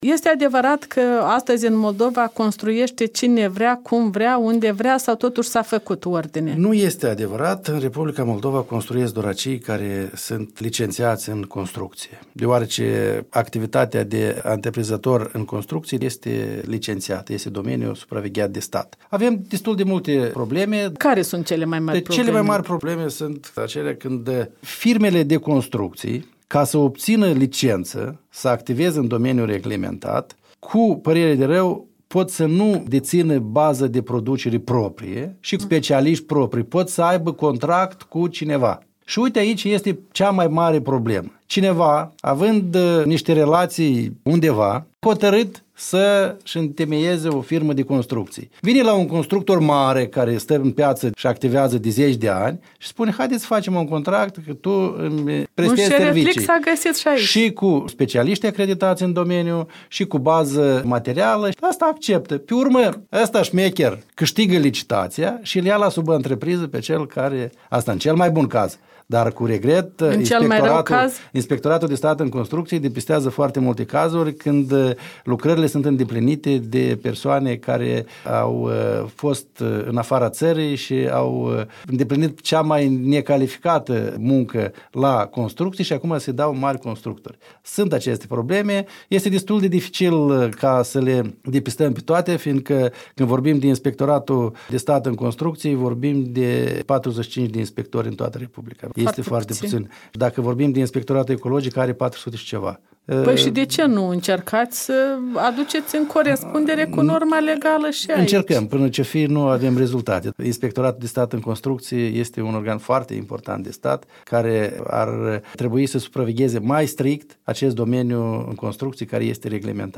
Interviu cu ministrul dezvoltării regionale şi construcţiilor Marcel Răducan.